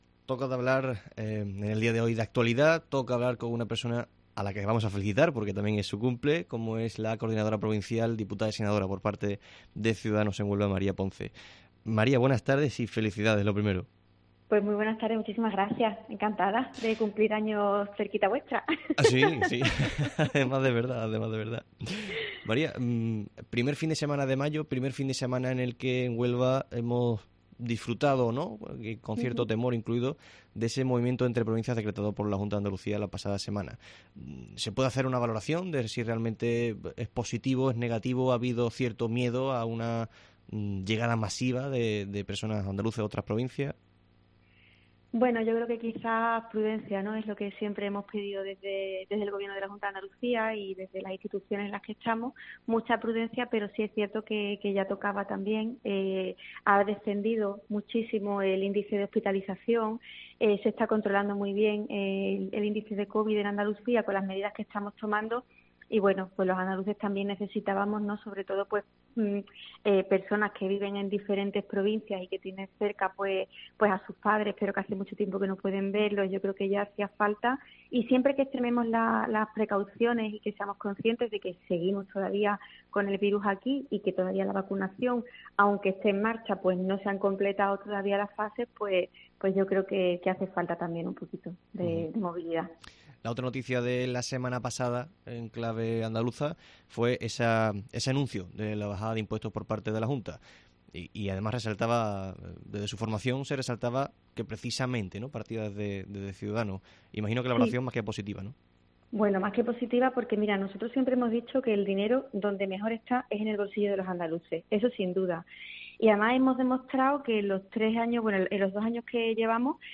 La coordinadora de Ciudadanos en la provincia de Huelva además de Diputada Provincia, María Ponce, ha atendido la llamada de COPE para responder...